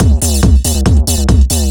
DS 140-BPM B4.wav